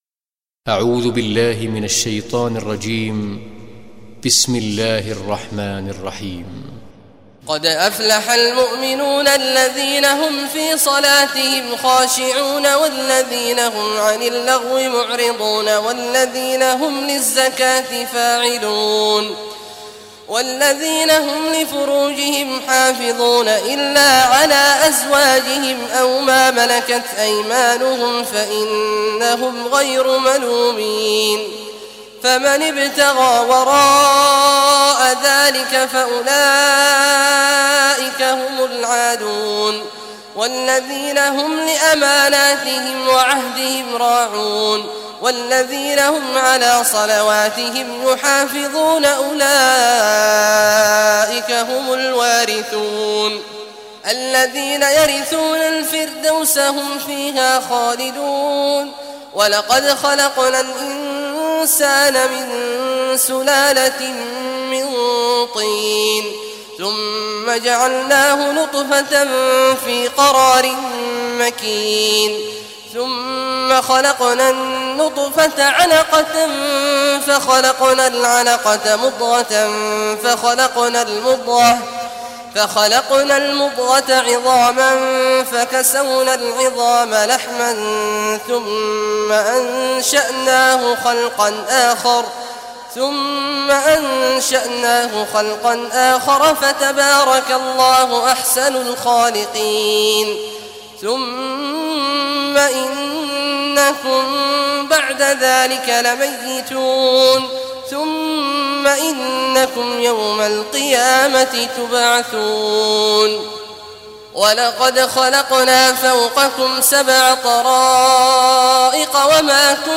Surah Al-Muminun Recitation by Sheikh Al Juhany
Surah Al-Muminun, listen or play online mp3 tilawat / recitation in Arabic in the beautiful voice of Sheikh Abdullah Awad al Juhany.